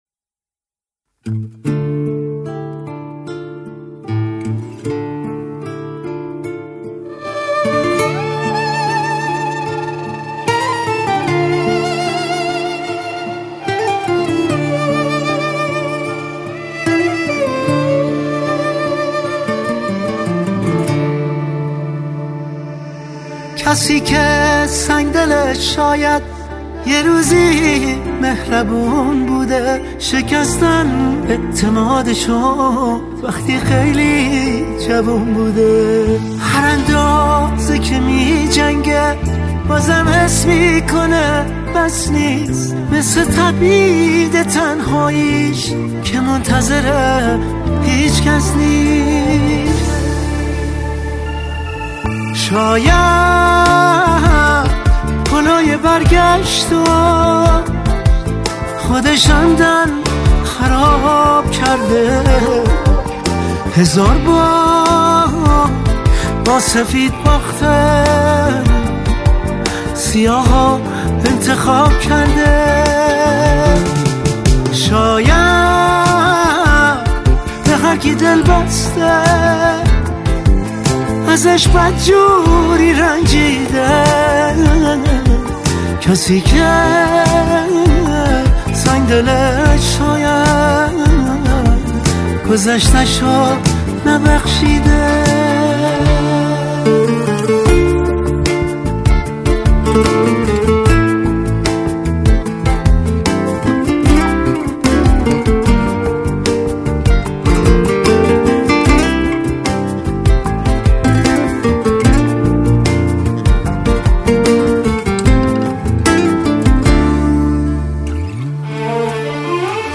با ریتم 4/4